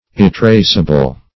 Search Result for " irretraceable" : The Collaborative International Dictionary of English v.0.48: Irretraceable \Ir`re*trace"a*ble\, a. Incapable of being retraced; not retraceable.